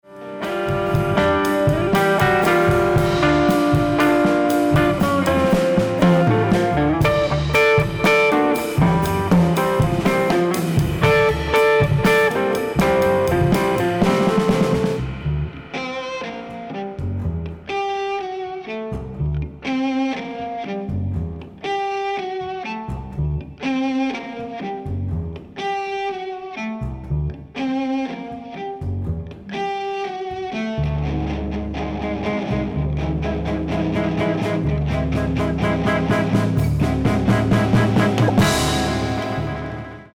爵士大提琴跨界專輯